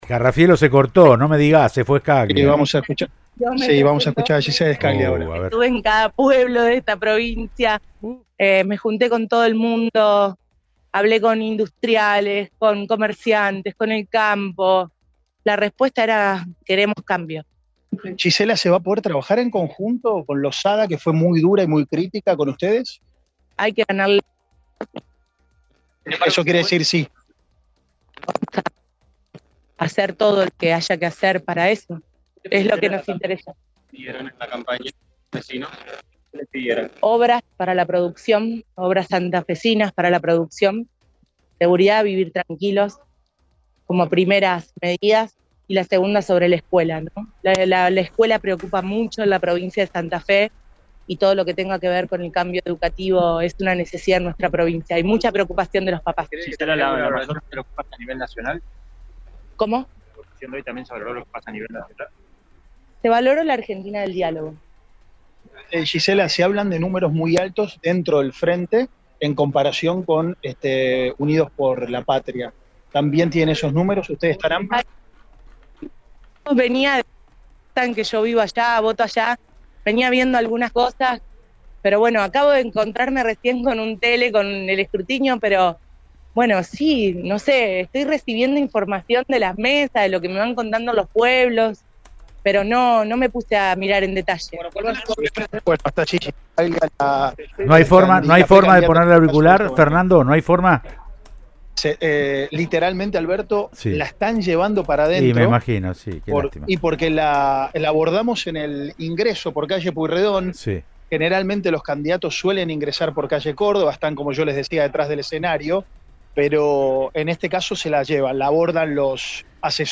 “Estamos recibiendo números muy optimistas con buenas perspectivas” le dijo la precandidata a vicegobernadora de Santa Fe en la fórmula junto a Maximiliano Pullaro por una de las líneas de Juntos por el Cambio dentro de la alianza “Unidos para Cambiar Santa Fe”, Gisela Scaglia al móvil de Cadena 3 Rosario mientras ingresaba al lugar en donde esperarán los resultados provisorios.